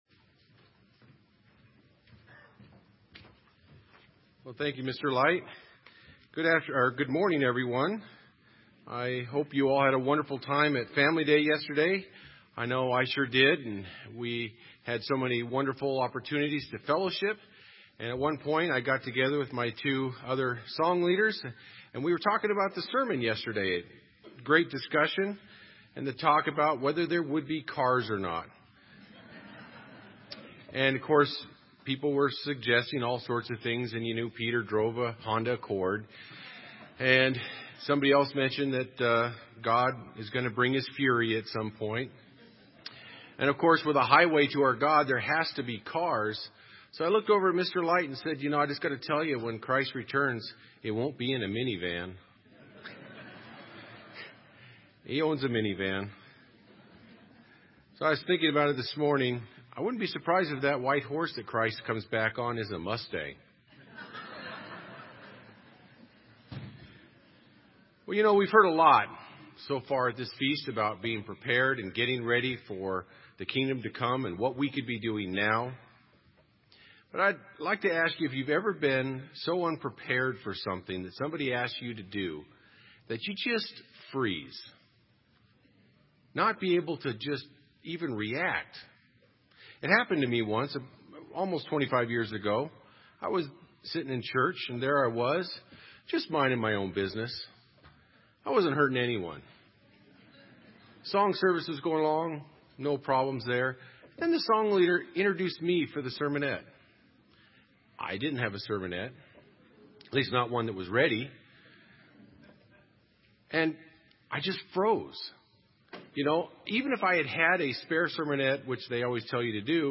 This sermon was given at the Bend, Oregon 2015 Feast site.